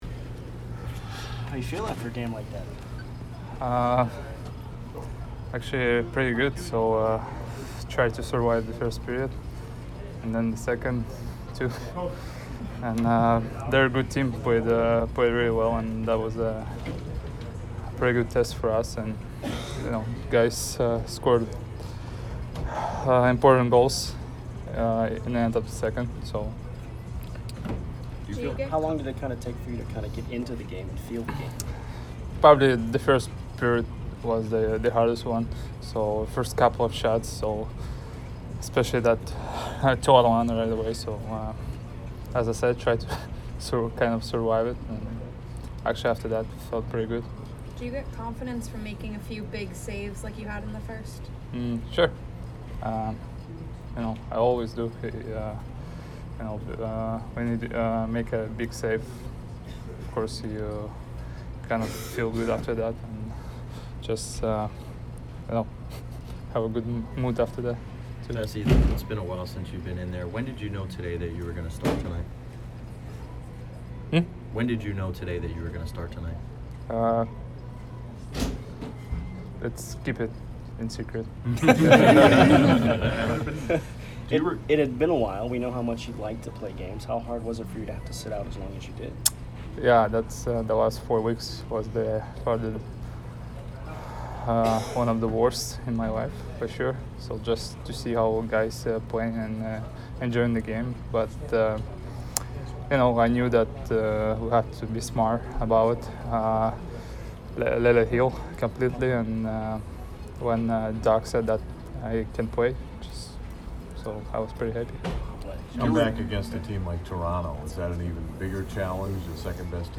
Andrei Vasilevskiy post-game 12/13